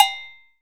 percussion 09.wav